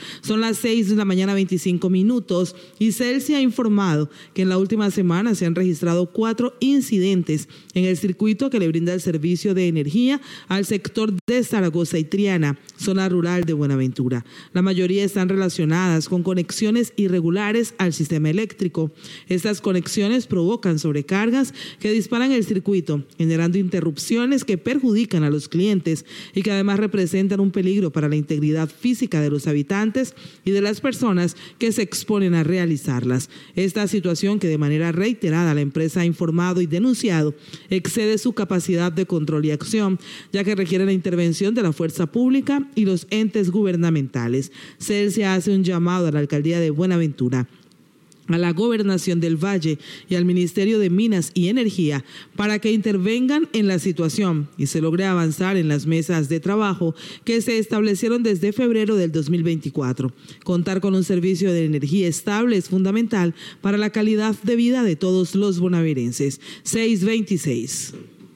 comunicado